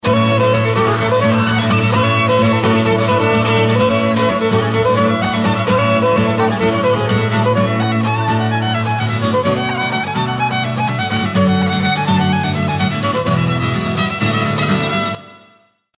An exciting track